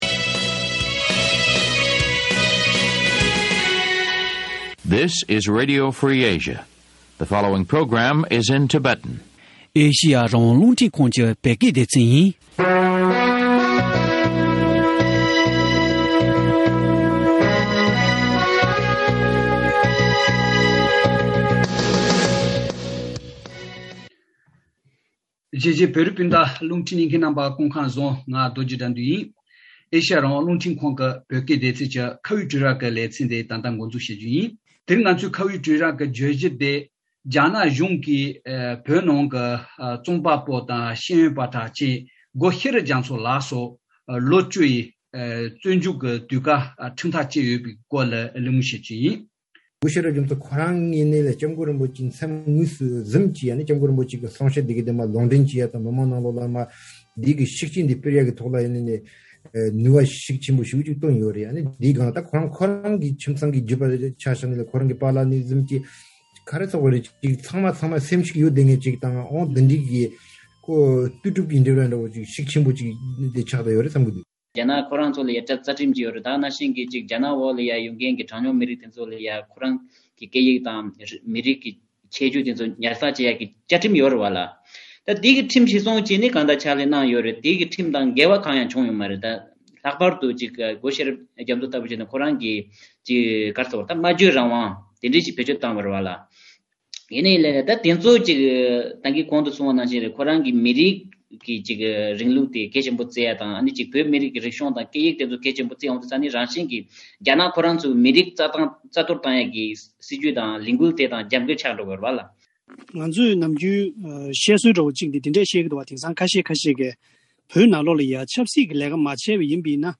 དེ་རིང་ཁ་བའི་གྲོས་རྭ་ལེ་ཚན་ནང་བཙན་བྱོལ་ནང་གི་ཤེས་ཡོན་པ་དང་འཐབ་རྩོད་པ་དང་ཉམས་ཞིབ་པ་བཅས་མི་སྣ་གསུམ་གདན་ཞུས་ཐོག་སྒོ་ཤེས་རབ་རྒྱ་མཚོ་ལ་རྒྱ་ནག་གིས་ལོ་བཅུའི་དུས་བཀག་བཙོན་འཇུག་ཁྲིམས་ཐག་བཅད་པའི་གནད་དོན་སྐོར་དབྱེ་ཞིབ་བྱེད་པ་ཡིན་།